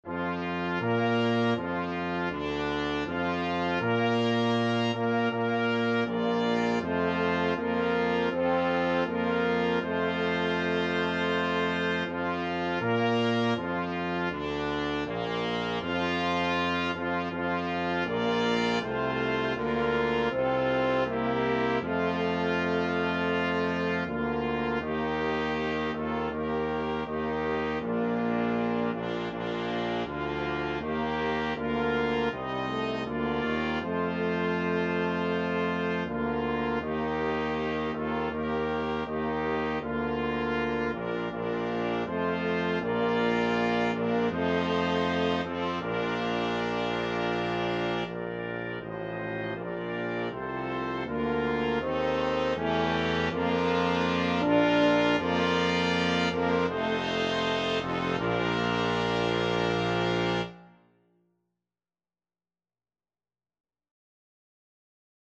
Trumpet 1Trumpet 2French HornTromboneTuba
Maestoso = c.80
4/4 (View more 4/4 Music)
Brass Quintet  (View more Easy Brass Quintet Music)
Traditional (View more Traditional Brass Quintet Music)